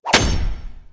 traphit_6.ogg